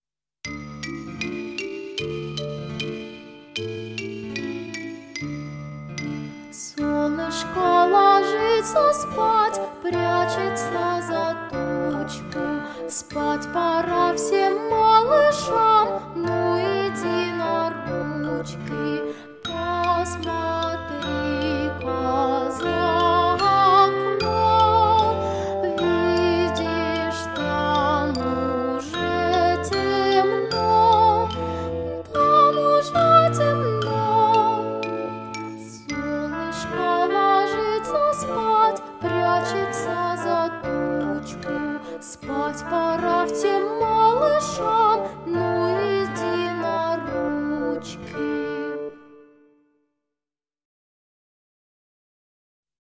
Современные, новые, популярные песни для детей 👶👧